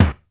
shot_hit_something.wav